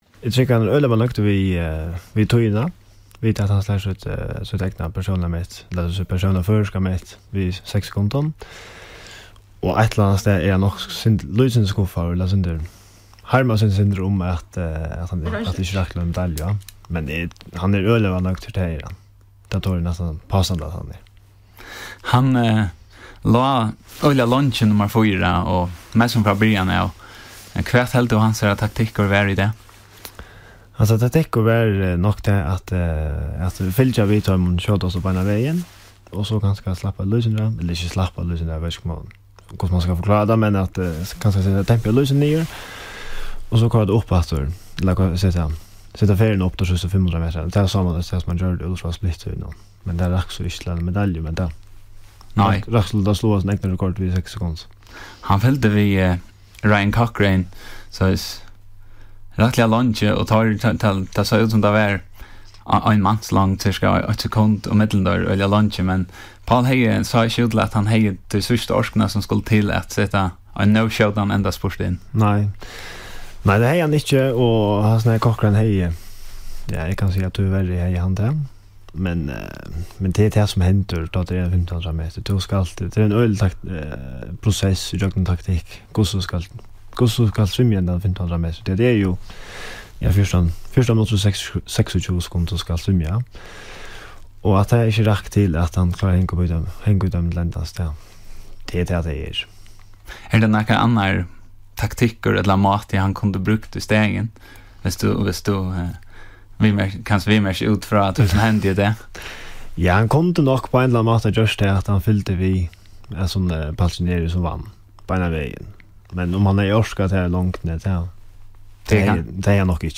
Útvarpssendingin Bólturin hjá Kringvarpi Føroya